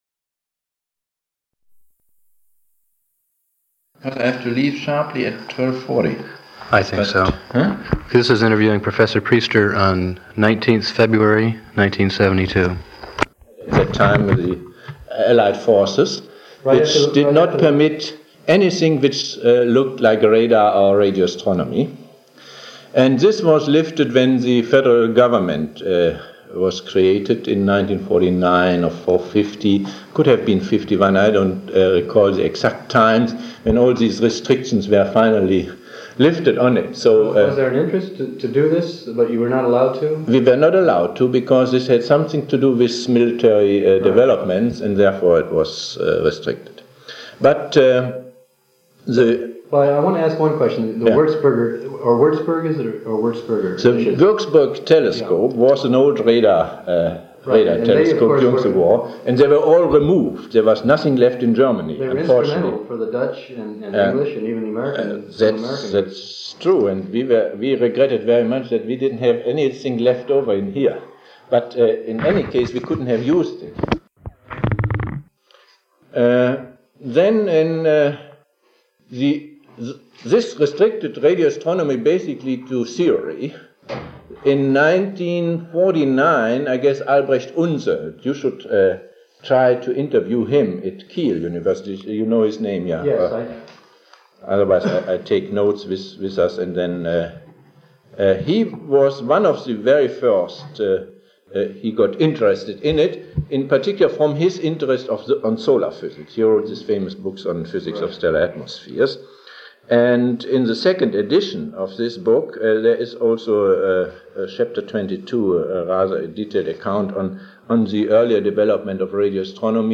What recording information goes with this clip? Location Bonn, Germany Original Format of Digital Item Audio cassette tape